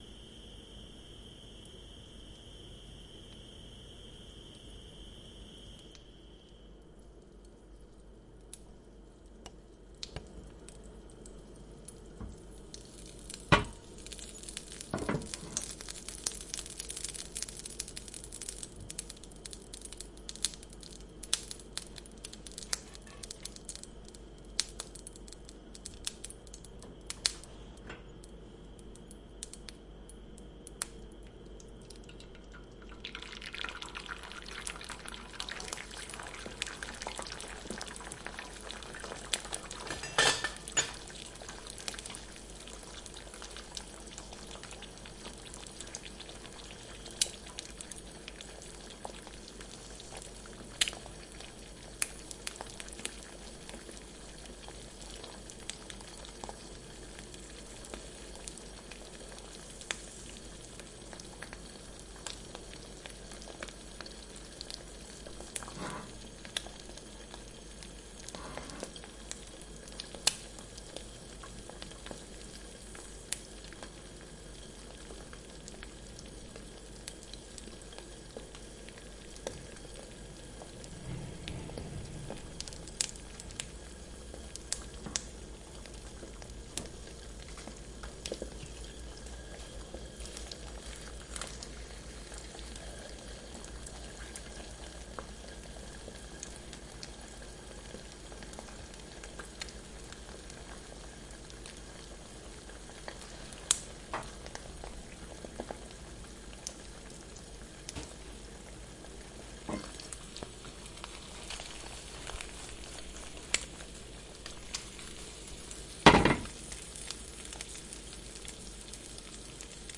斯密特厨房 " 煎鸡蛋
描述：在Zoom H4n上录制96 KHz 32位立体声
Tag: 厨房 国内的声音 现场记录 烹饪